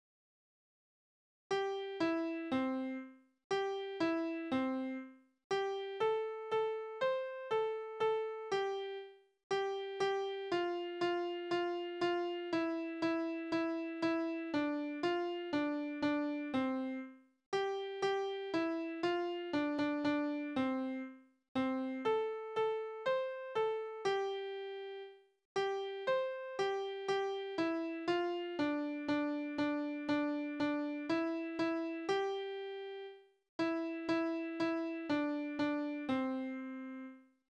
Kindertänze:
Tonart: C-Dur
Taktart: C (4/4)
Tonumfang: Oktave
Besetzung: vokal
Anmerkung: Bezeichnung: Spiellied der Schulkinder